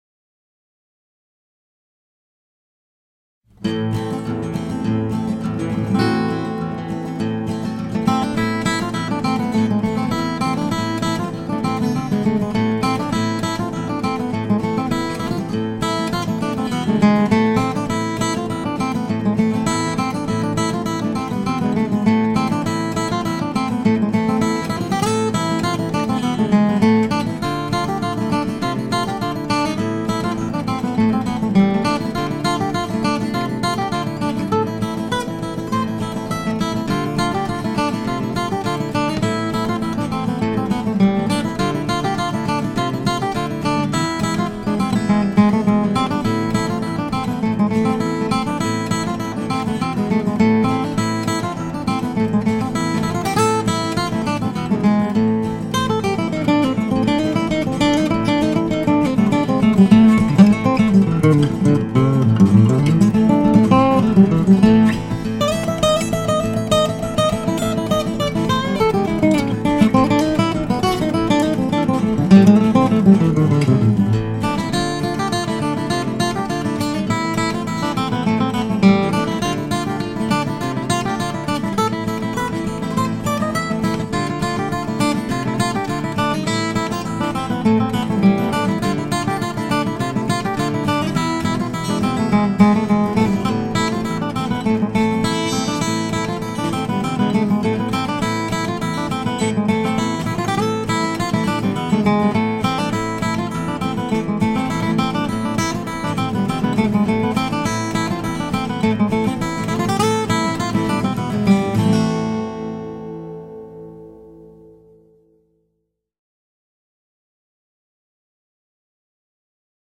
Old Time Special 12 fret 000 Adirondack Spruce Top with snow flake inlays